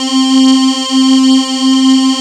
MELLOW C4.wav